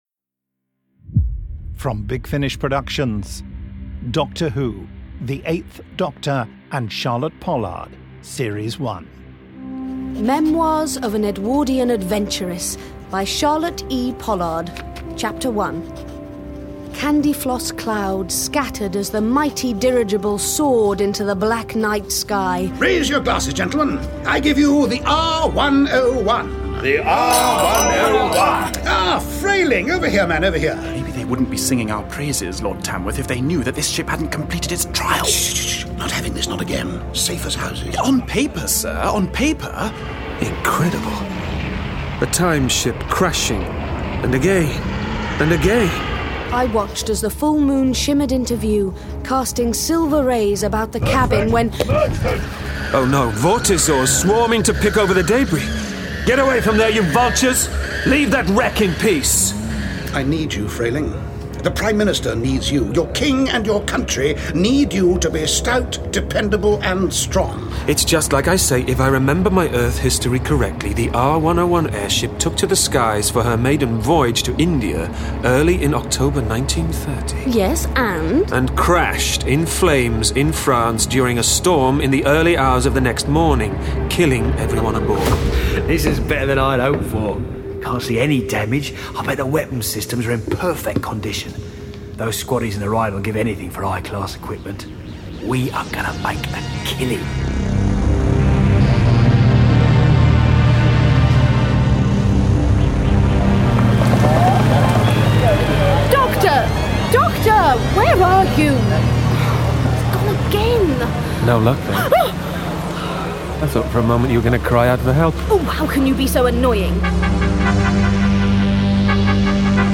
Starring Paul McGann India Fisher